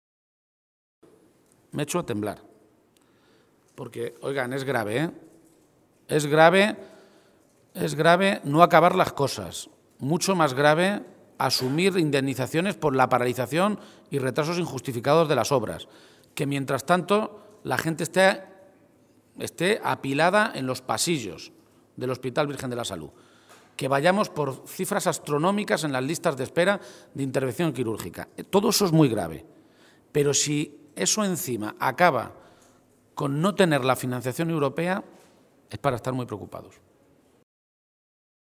Emiliano García-Page, Secretario General del PSOE de Castilla-La Mancha
Cortes de audio de la rueda de prensa